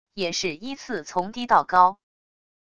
也是依次从低到高wav音频